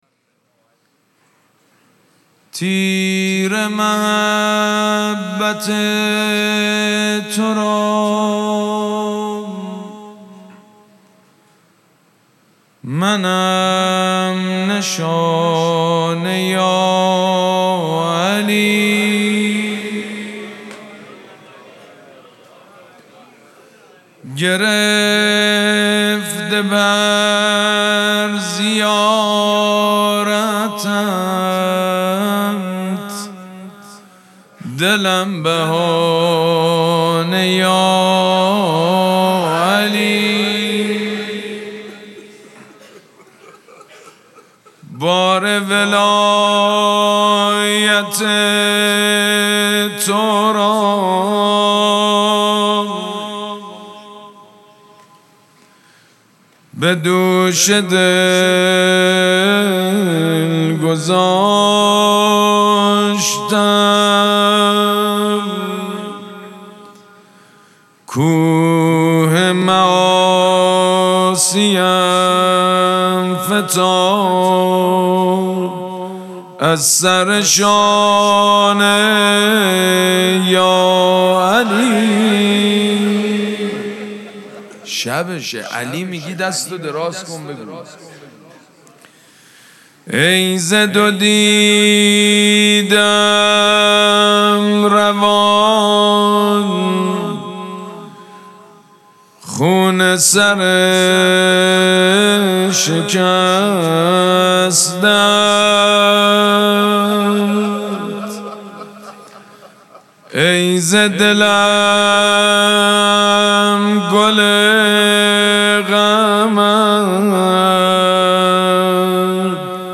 مراسم مناجات شب نوزدهم ماه مبارک رمضان
شعر خوانی
مداح
حاج سید مجید بنی فاطمه